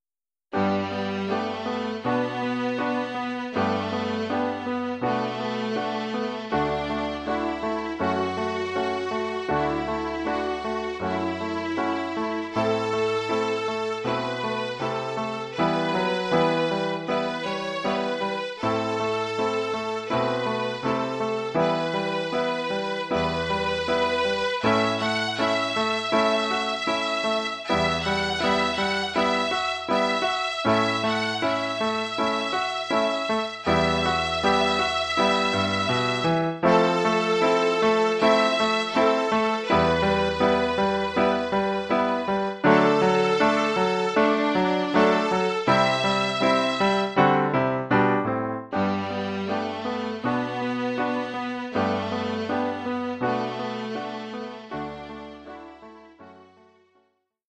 Formule instrumentale : Violon et piano
Oeuvre pour violon et piano.
Niveau : débutant (1er cycle, 1ère année).